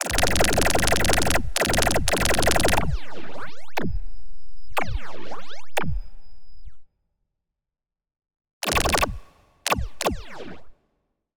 Laser Gun 3
Laser-Gun-03-Example.mp3